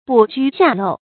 补苴罅漏 bǔ jū xià lòu
补苴罅漏发音
成语注音ㄅㄨˇ ㄐㄨ ㄒㄧㄚˋ ㄌㄡˋ
成语正音罅，不能读作“lǔ”。